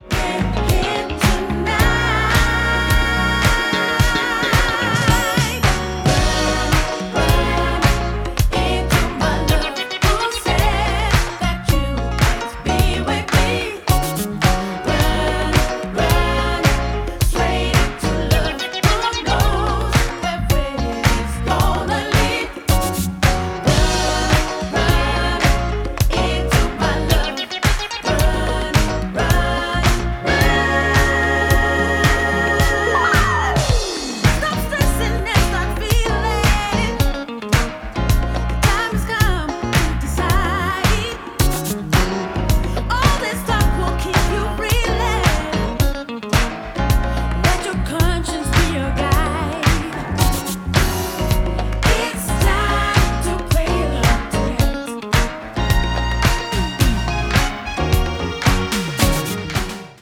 and bolstered throughout by a dedicated brass section.